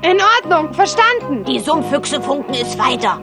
Na da hab ich mir wieder was aufgehalst Über ein Dutzend "Kinderrollen" mit Sprechern, die (wie im Original) wohl keine Kinder mehr sind.